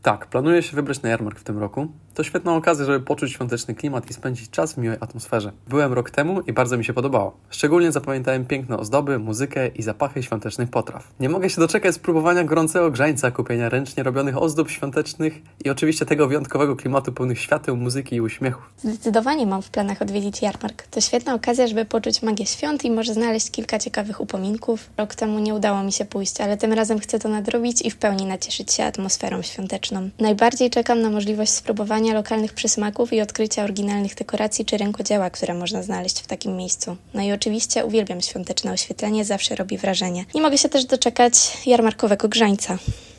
Mieliśmy przyjemność zapytać studentów naszego uniwersytetu, czy wybierają się na tegoroczny jarmark.